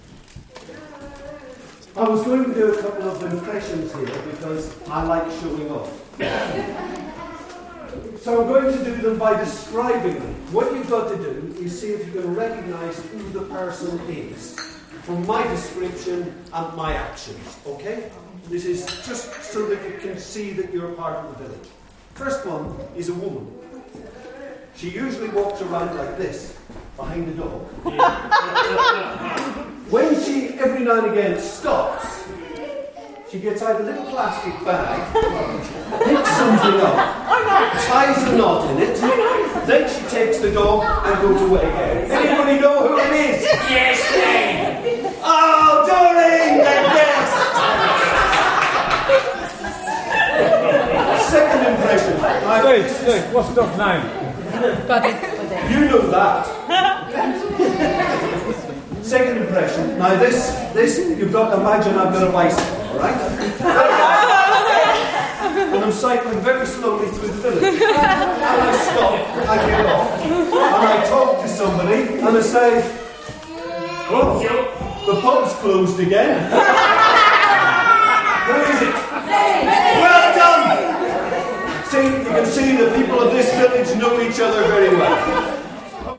At Moulton Seas Ends Got Talent in June